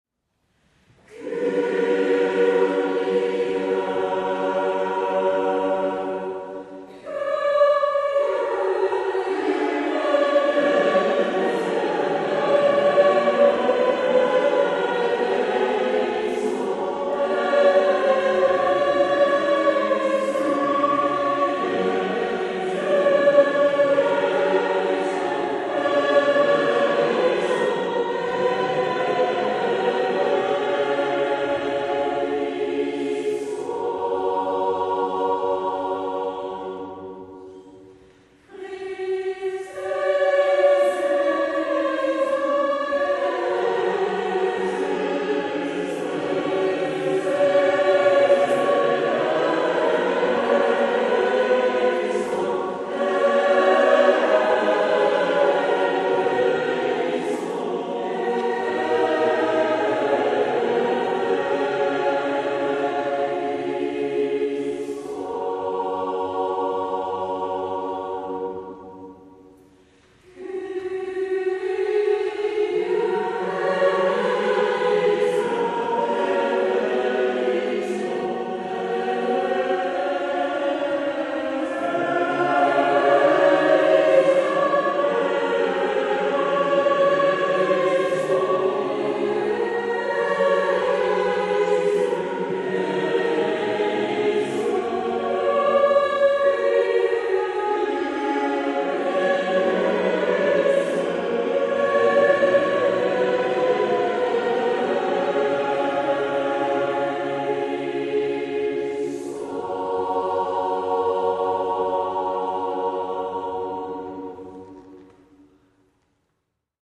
Eucharistiefeier mit Bischof Dr. Alois Schwarz
Kyrie: Missa in G, Casali 2 MB Gesamtchor Antwortpsalm: Kehrvers + Magnificat GL 597/2 3 MB Kantorengruppe Halleluja: Herrlichkeit, Macht, Lp 153 1 MB NGL (Neues Geistliches Lied) Gabenbereitung: Let us break, bread together 2 MB Kammerchor Sanctus+Benedictus: Missa in G, Casali 2 MB Gesamtchor